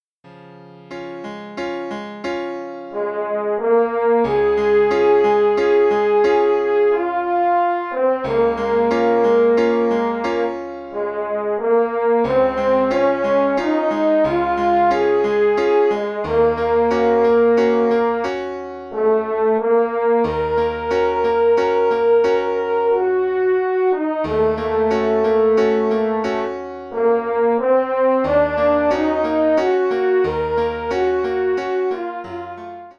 concert piece for horn and piano